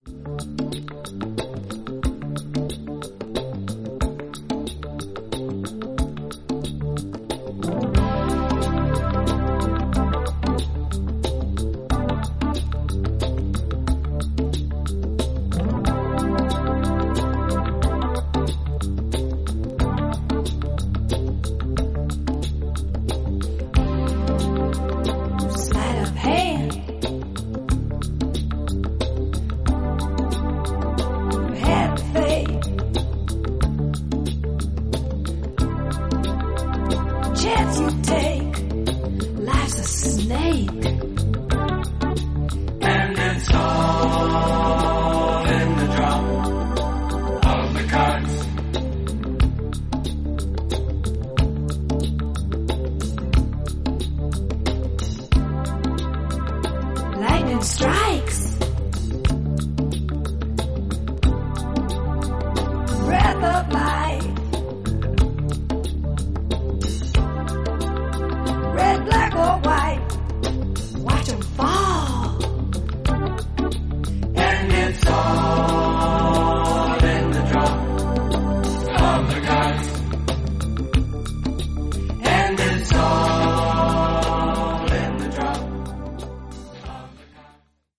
3拍子で展開するニューウェーヴィーなアフロ・コズミック・トラック